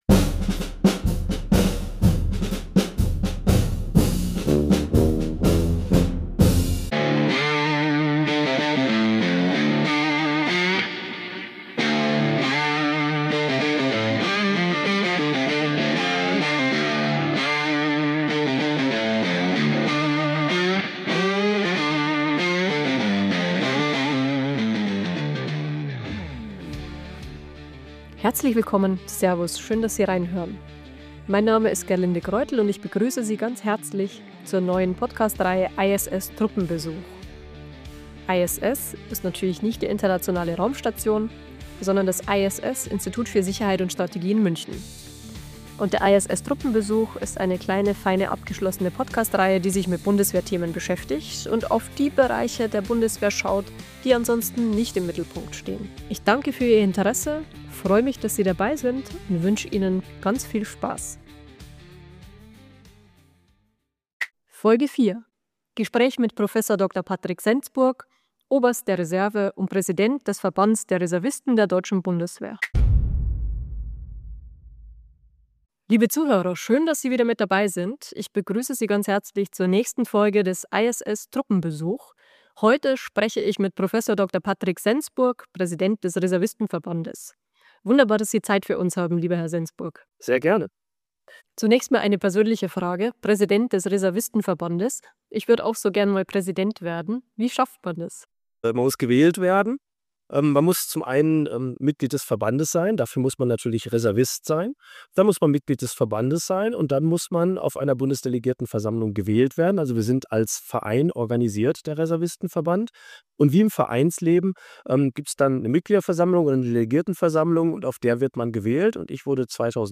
Grund genug, mit dem Präsidenten des deutschen Reservistenverbands, Prof. Dr. Patrick Sensburg, über die Rolle, die Entwicklung und die Herausforderungen der Reserve zu sprechen. Wie ist die Reserve aufgestellt?
Das tiefgründige Gespräch zeigt einmal mehr, dass Verteidigung eine gesamtgesellschaftliche Aufgabe ist.